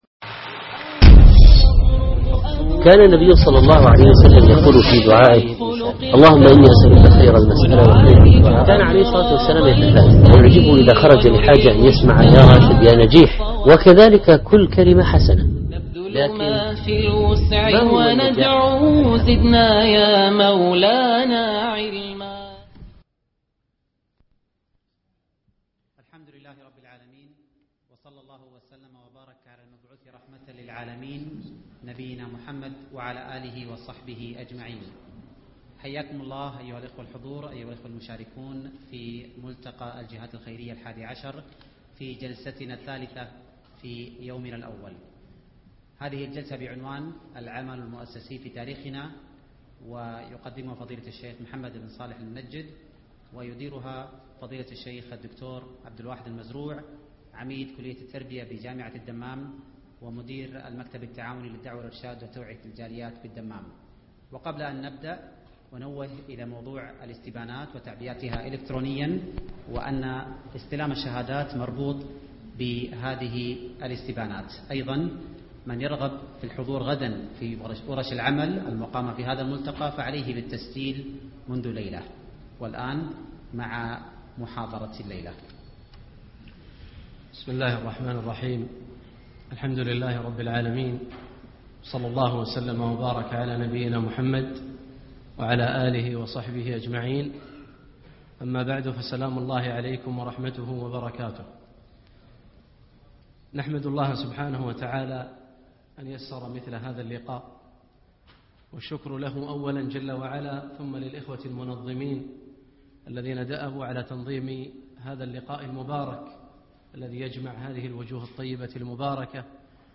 العمل المؤسسي في تاريخنا-محاضرات - الشيخ محمد صالح المنجد